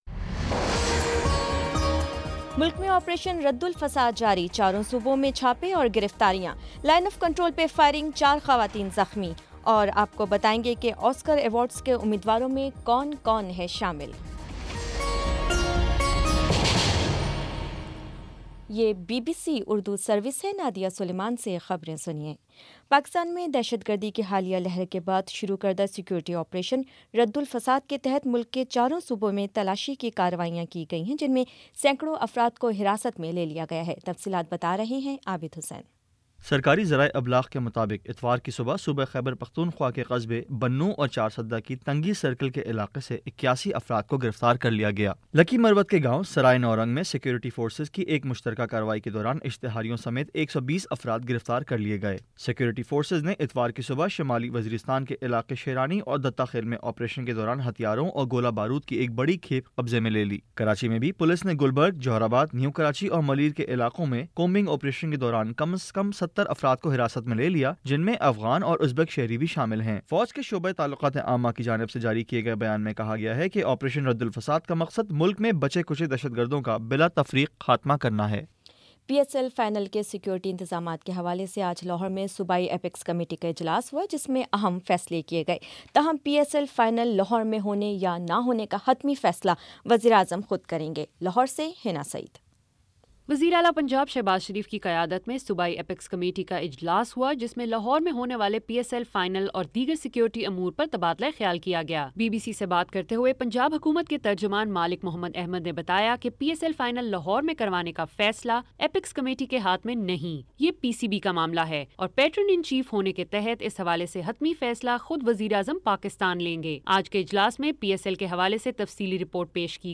فروری 26 : شام پانچ بجے کا نیوز بُلیٹن